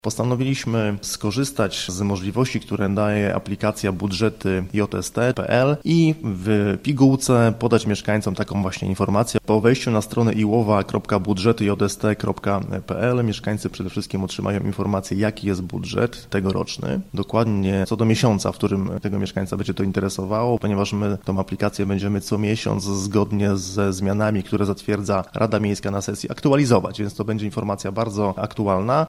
– To odpowiedź na często zadawane przez mieszkańców pytanie o sposoby wydatkowania pieniędzy podatników – informuje Paweł Lichtański, burmistrz Iłowej: